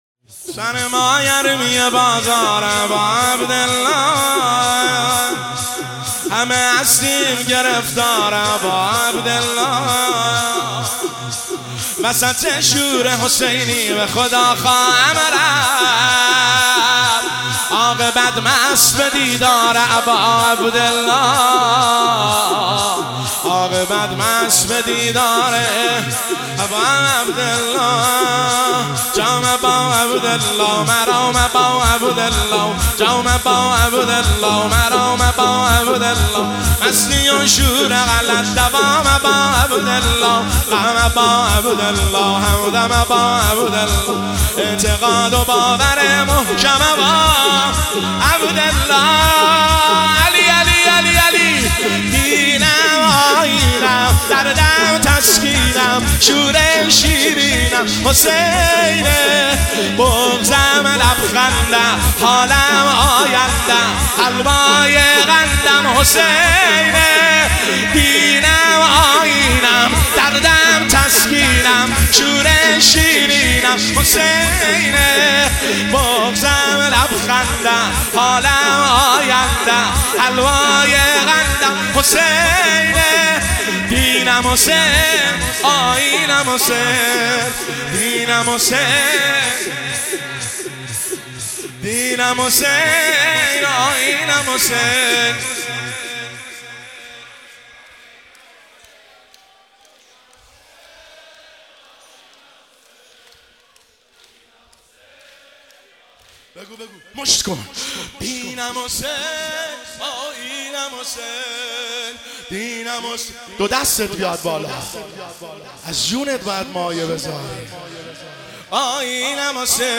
شور  شب دوم محرم الحرام 1404
هیئت خادم الرضا قم